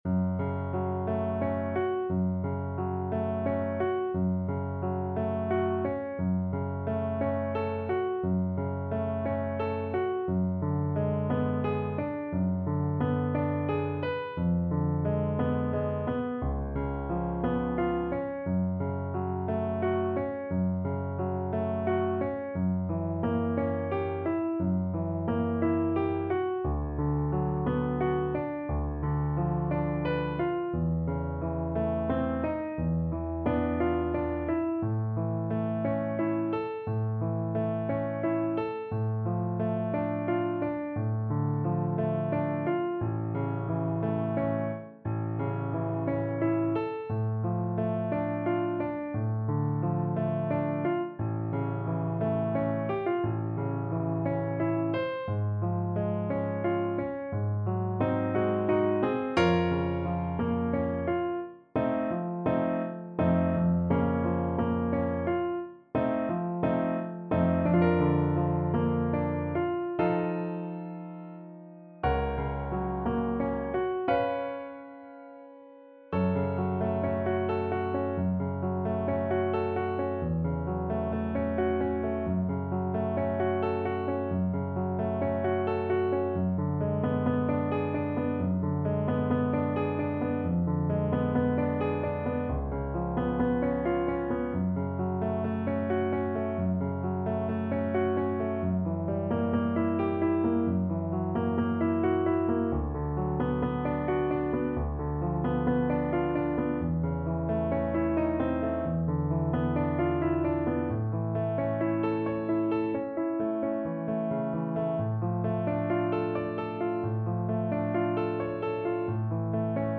Play (or use space bar on your keyboard) Pause Music Playalong - Piano Accompaniment Playalong Band Accompaniment not yet available transpose reset tempo print settings full screen
F# minor (Sounding Pitch) (View more F# minor Music for Viola )
~ = 88 Malinconico espressivo
3/4 (View more 3/4 Music)
Classical (View more Classical Viola Music)